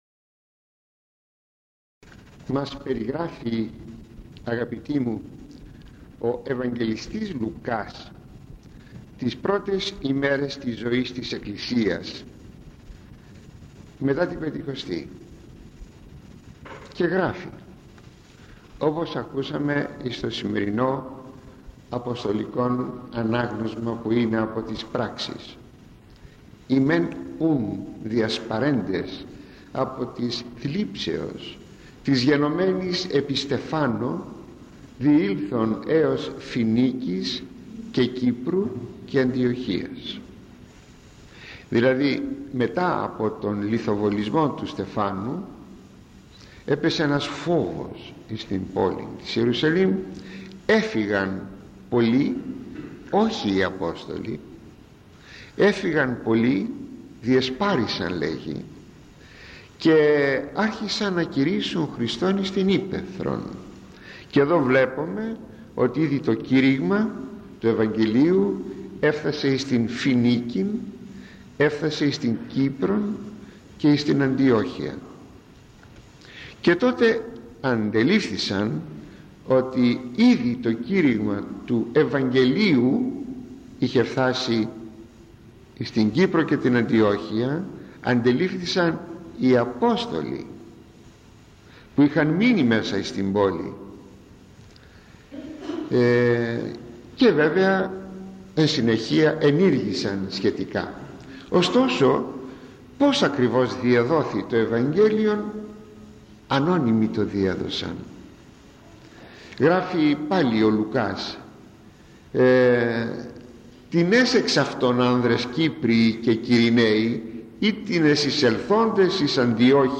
Ηχογραφημένη ομιλία στο Αποστολικό Ανάγνωσμα της Κυριακής της Σαμαρείτιδος